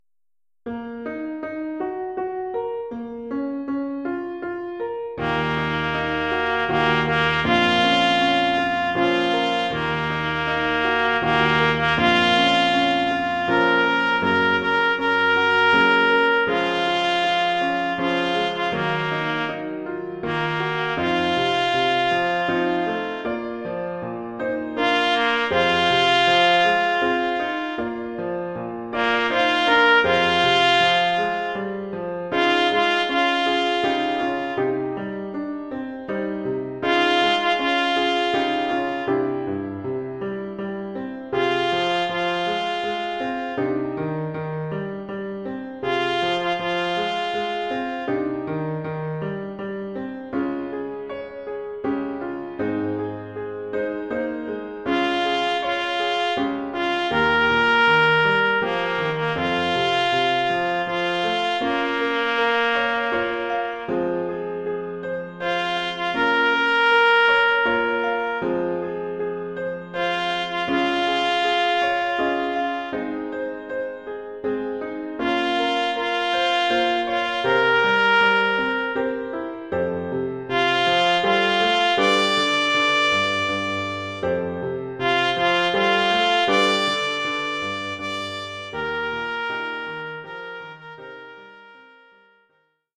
Formule instrumentale : Clairon ou clairon basse et piano
clairon basse et piano.